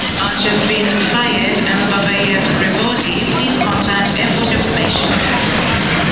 Announcers were tricked into saying them under the pretence that they were foreign names.
"We'd go and sit on the balcony at Terminal 3 at Heathrow, directly under one of the speakers as the roof is low.
We put the tape machine in our bag with the microphone poking out of the top.